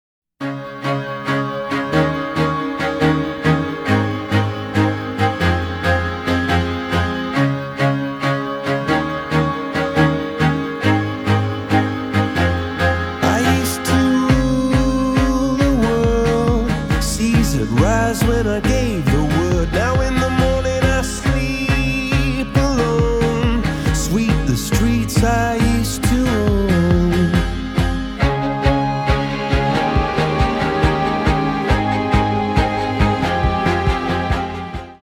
• Качество: 320, Stereo
мужской вокал
спокойные
приятные
виолончель
baroque pop